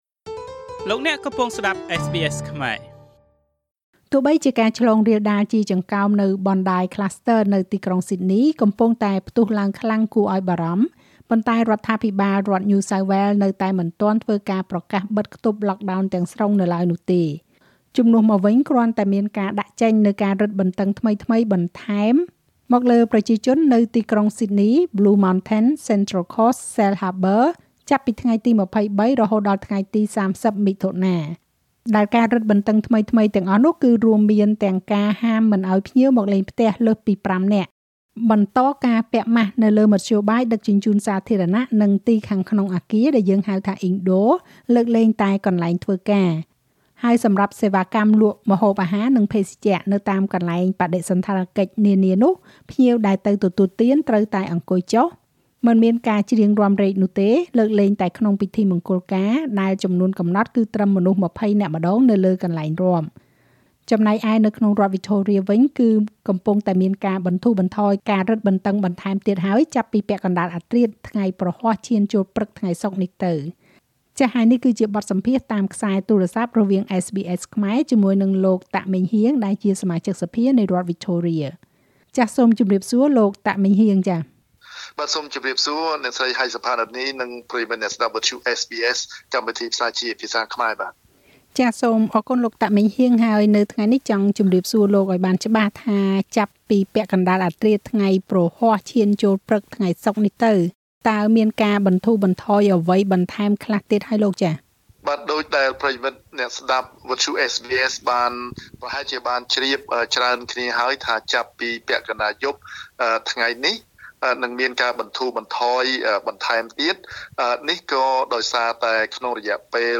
បើទោះបីជា ការឆ្លងរាលដាលជាចង្កោម Bondi Cluster នៅទីក្រុងស៊ីដនីកំពុងតែផ្ទុះឡើងខ្លាំងគួរឲ្យបារម្ភ ប៉ុន្តែរដ្ឋាភិបាលរដ្ឋញូសៅវែលនៅតែមិនប្រកាសបិទខ្ទប់lockdown នោះទេ។ ចំណែកឯនៅរដ្ឋវិចថូរៀវិញ នឹងមានការបន្ធូរបន្ថយការរឹតបន្តឹងបន្ថែមទៀតចាប់ពីពាក់កណ្តាលអធ្រាត្រថ្ងៃព្រហស្បតិ៍ឈានចូលព្រឹកថ្ងៃសុក្រនេះទៅ។ សូមស្តាប់បទសម្ភាសន៍ជាមួយលោក តាក ម៉េងហ៊ាង សមាជិកសភានៃរដ្ឋវិចថូរៀ។